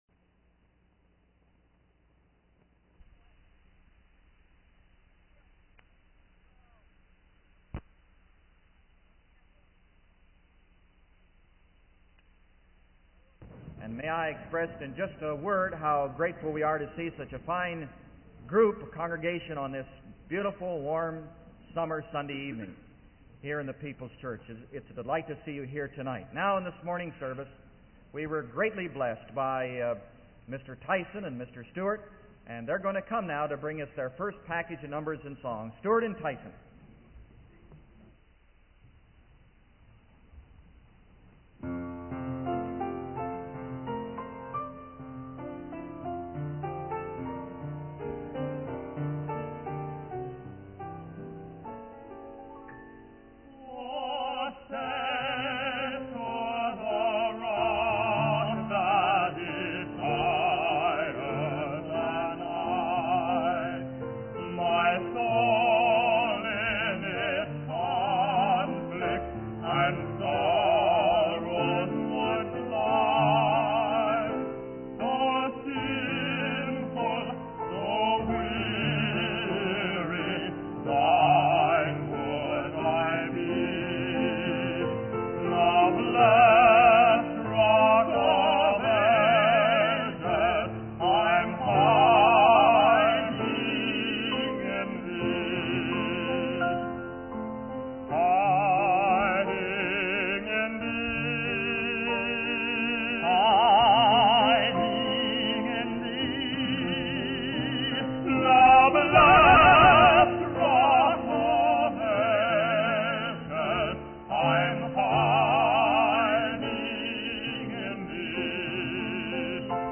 In this sermon, the speaker begins by reading the well-known verses from Matthew 28:18-20, known as the Great Commission. He emphasizes the importance of making disciples and calling people to express the Lordship of Jesus Christ in every area of life. The speaker highlights the need for disciplined time in studying God's Word and encourages students to meet with God through Bible study.